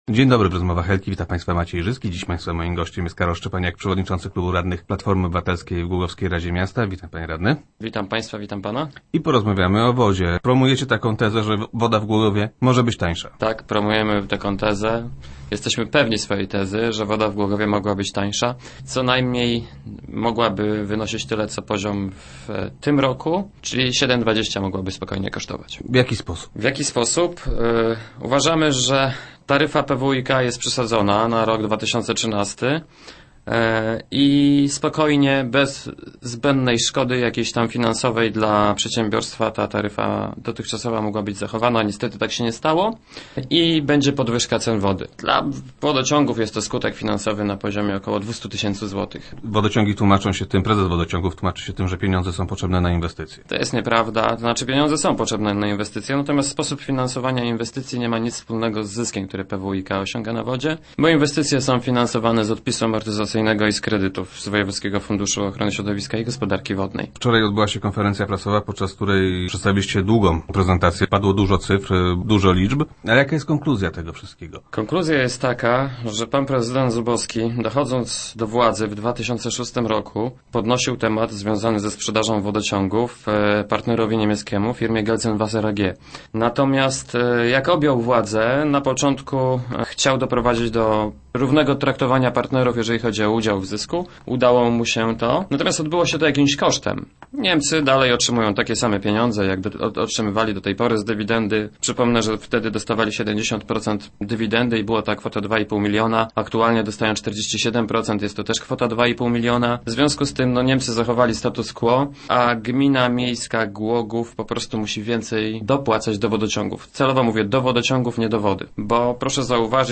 Zapewnia o tym Karol Szczepaniak, szef klubu PO w radzie miasta, który był gościem Rozmów Elki.